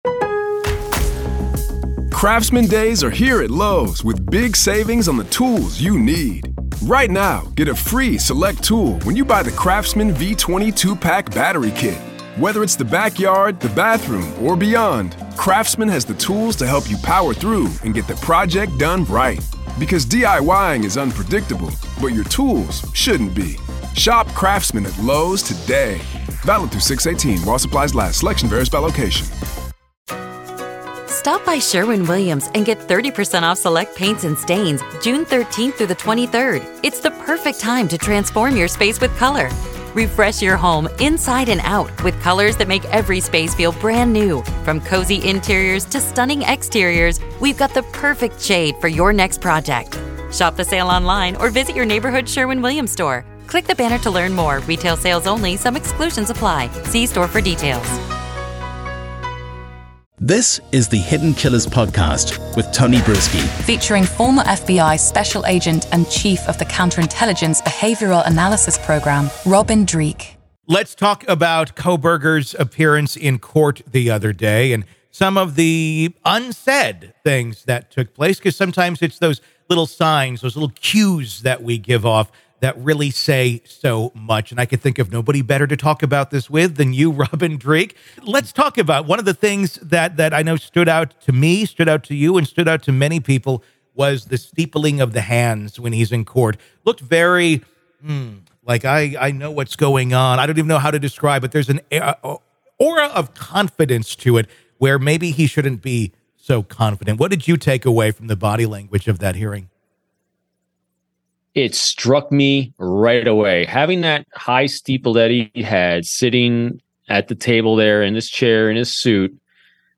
In a riveting conversation